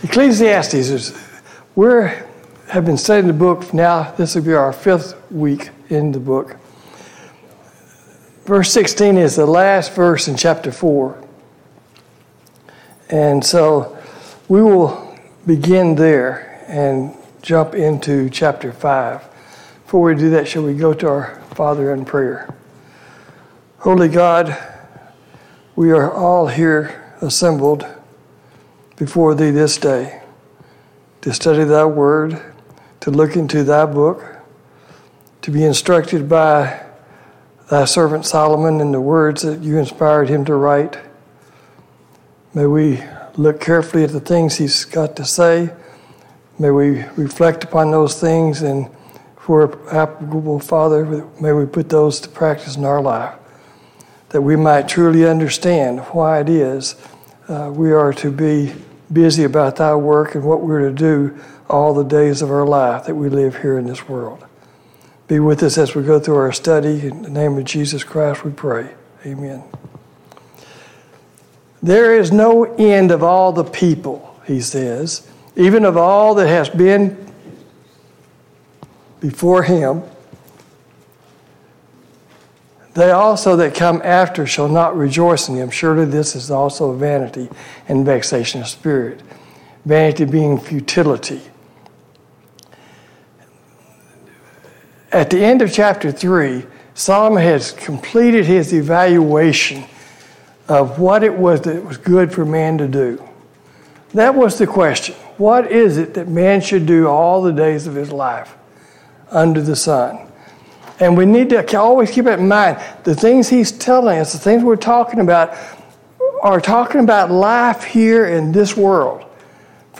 A Study of Ecclesiastes Service Type: Sunday Morning Bible Class « 37.